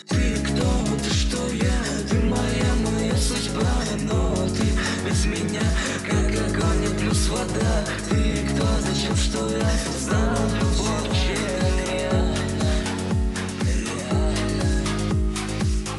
• Качество: 321, Stereo
мужской вокал
dance
club
клубняк